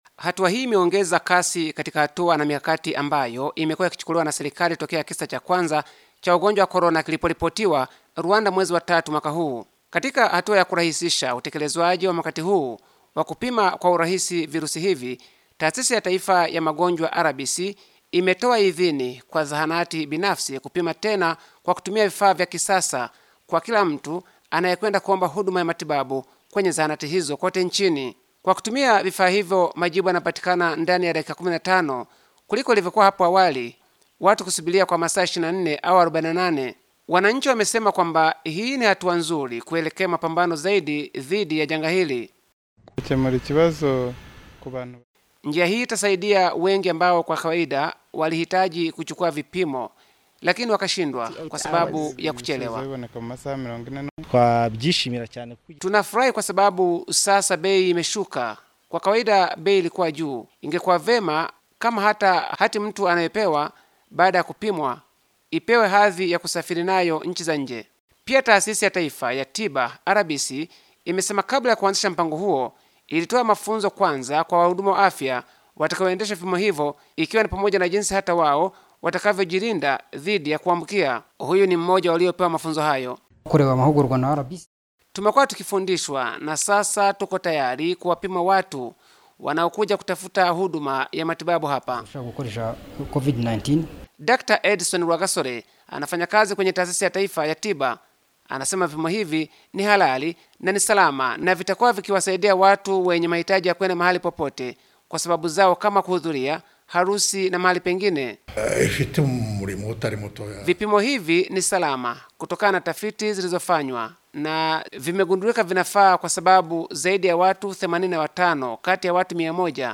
Kutoka Kigali